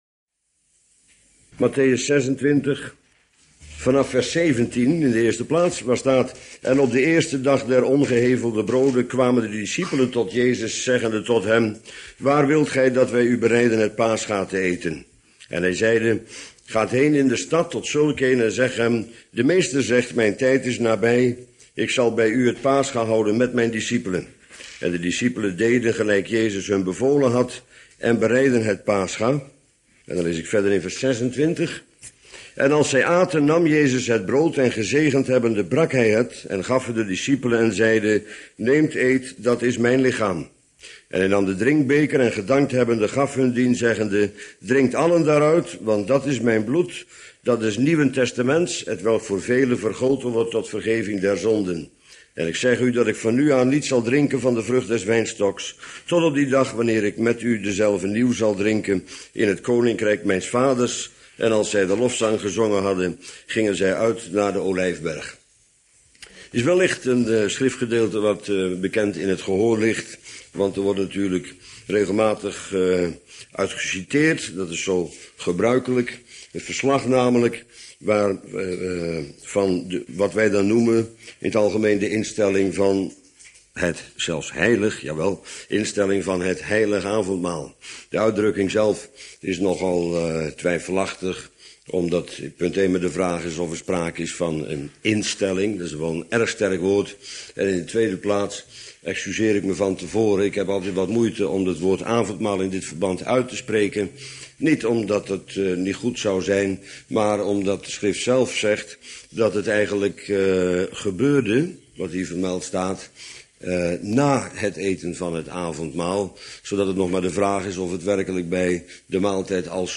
Brood en Wijn - Bijbels Panorama bijbellezing op mp3 audio